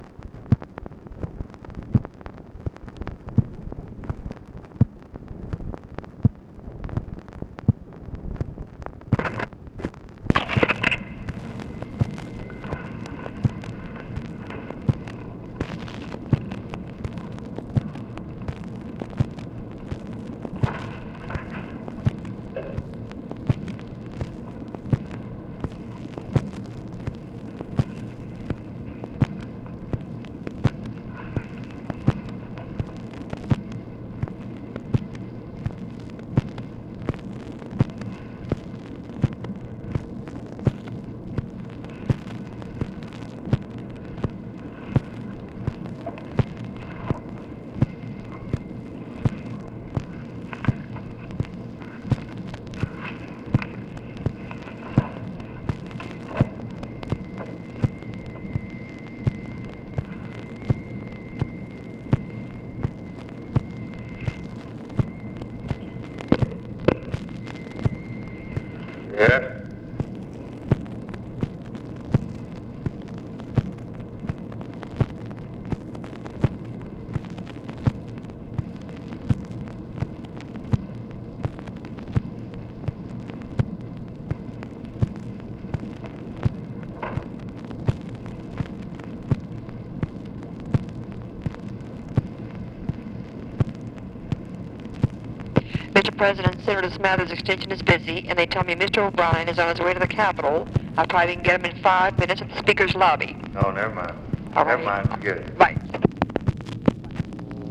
Conversation with TELEPHONE OPERATOR